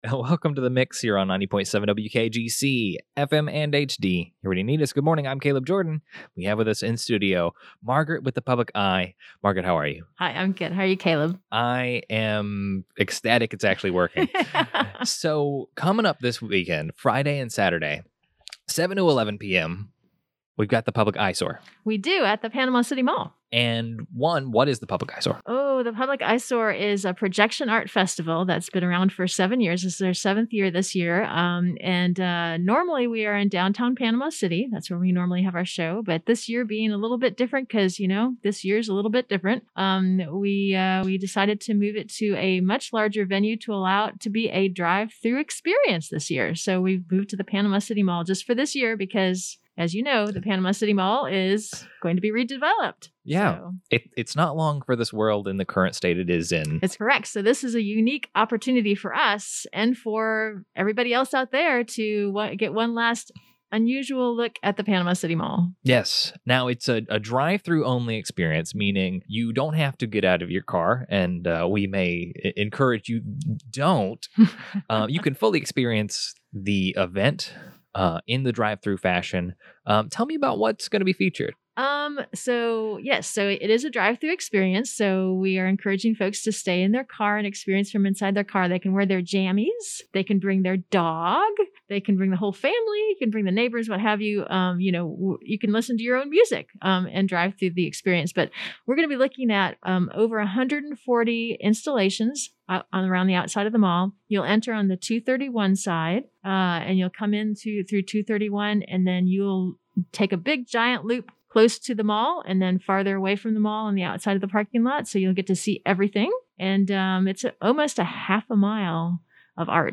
publiceyesoar_interview.mp3